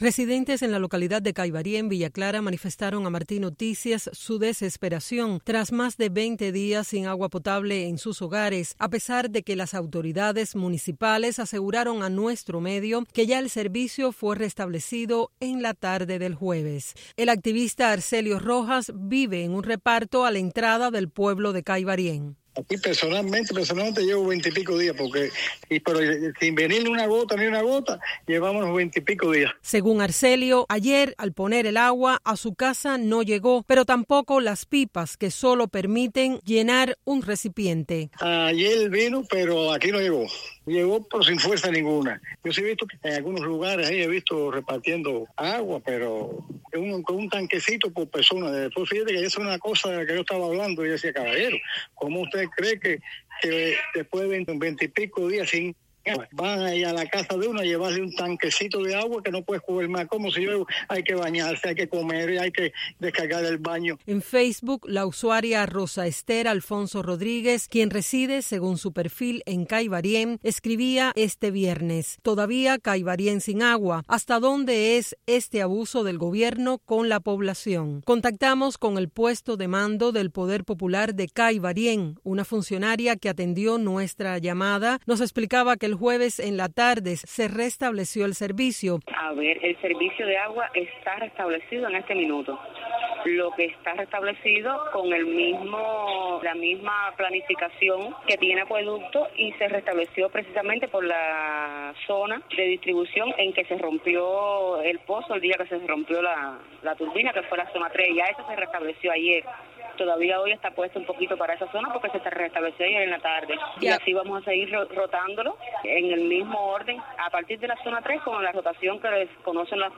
Noticias de Radio Martí
Mientras las autoridades anuncian el restablecimiento del servicio de agua en Caibarién, Villa Clara, residentes, consultados por Radio Martí noticias, se quejan de las deficiencias en la distribución del vital líquido.